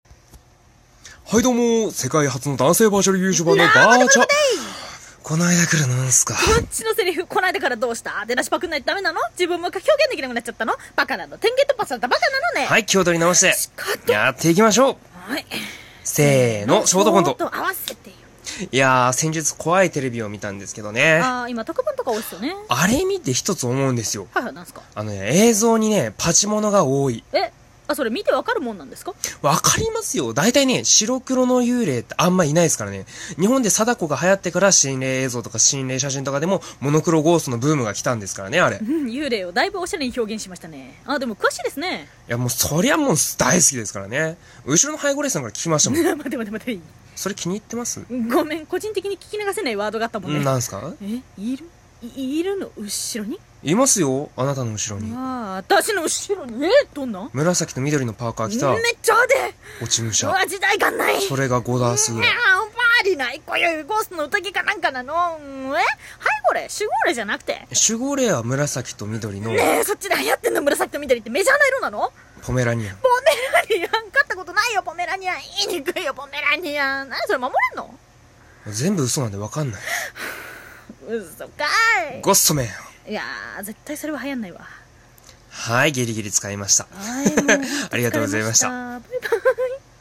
漫才してみたwwwwwww